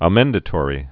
(ə-mĕndə-tôrē)